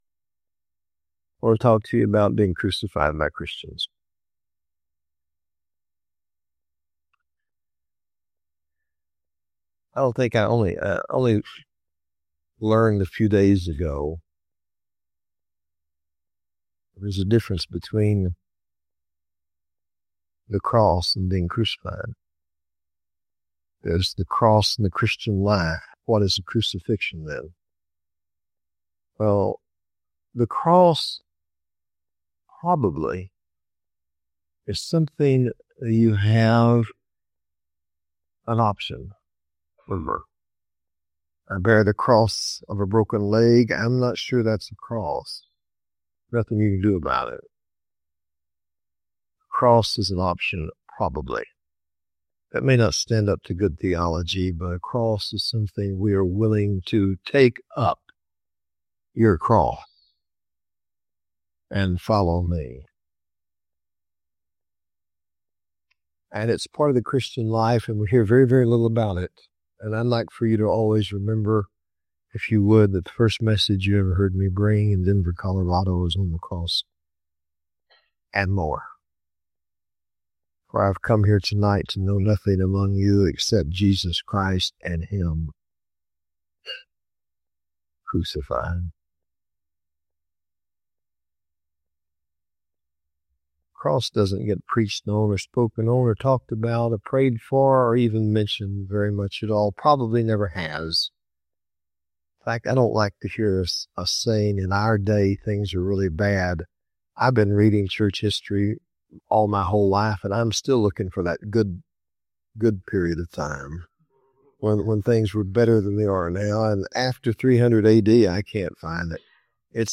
A profound teaching on being crucified by Christians, the meaning of unjust suffering, and how God uses crucifixion to transform believers.